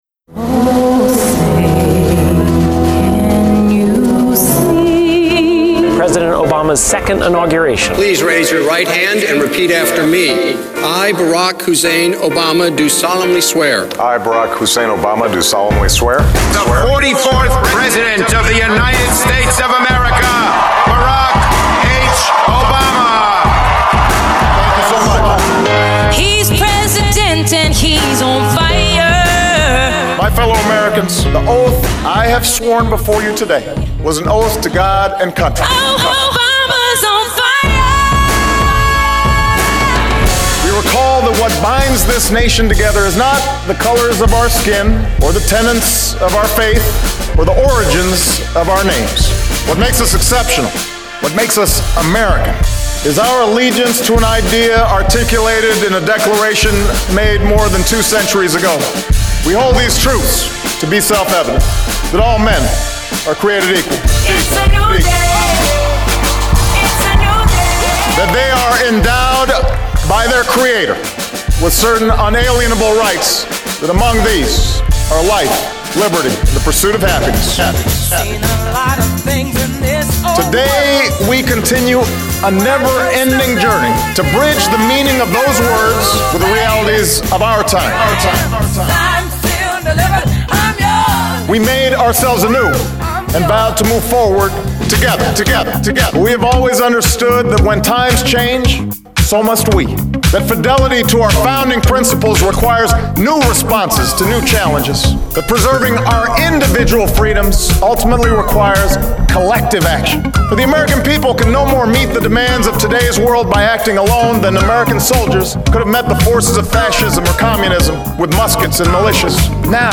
Enjoy this mix as we listen to our President Obama at the 2013 Inauguration